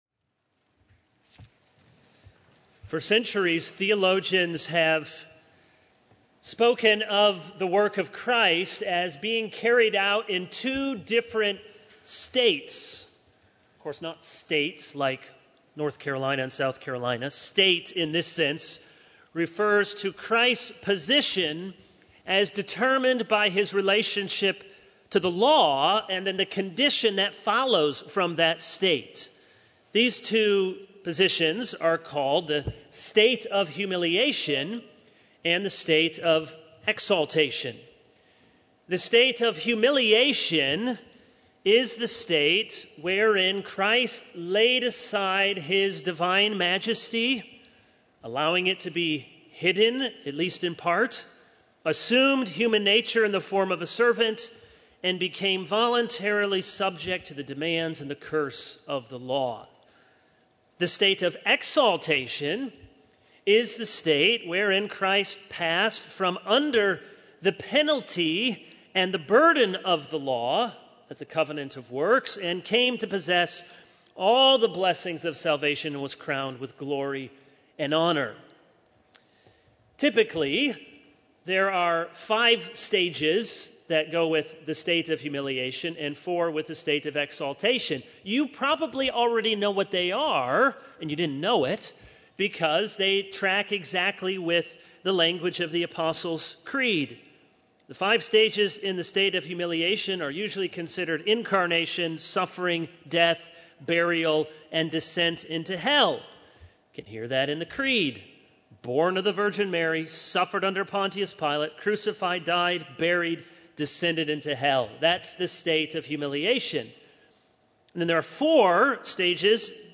All Sermons Learning to Love: Hospitality 0:00 / Download Copied!